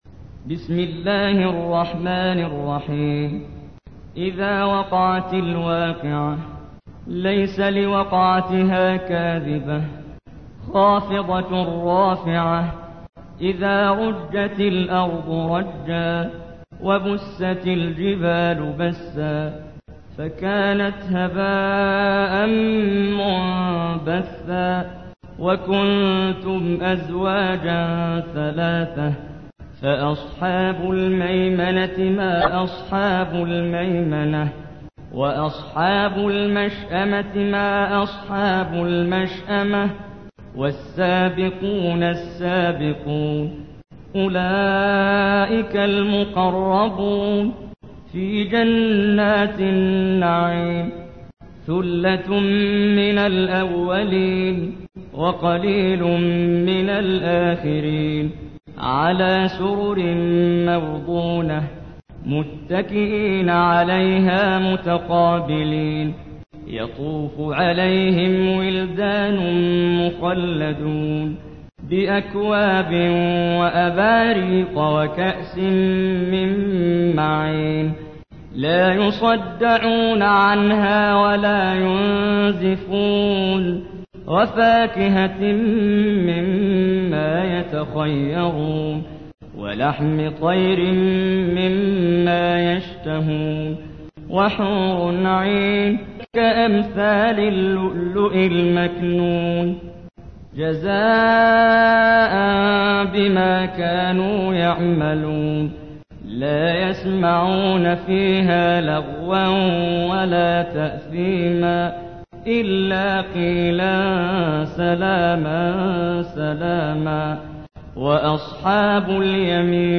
تحميل : 56. سورة الواقعة / القارئ محمد جبريل / القرآن الكريم / موقع يا حسين